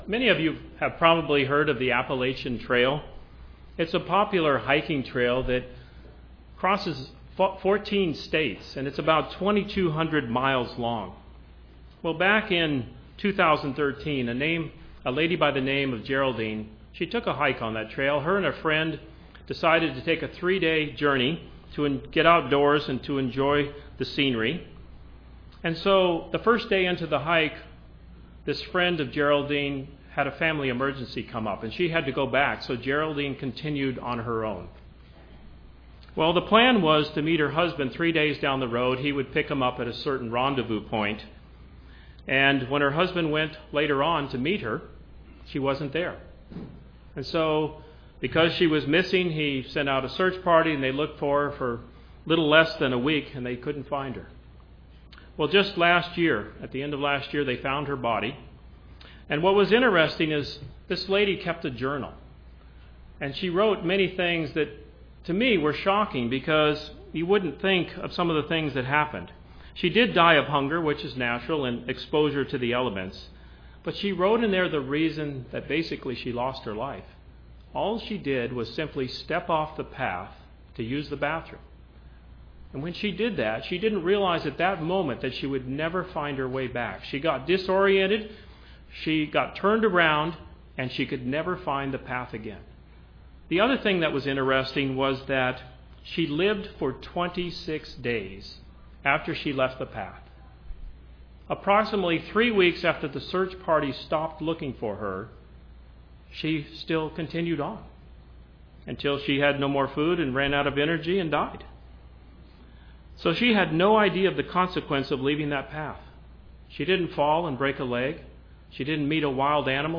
Given in Orlando, FL
Print Stirring up the Gift of God UCG Sermon Studying the bible?